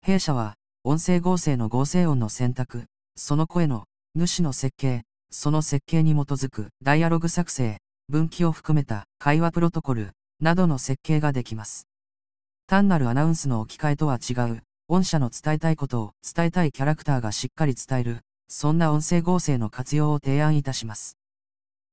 音声合成とは？
こちらもアニメっぽい男性の声。ちょっとささやきに近いやさしめの言葉は癒し系のネタにぴったり。
男声優合成の説明部.wav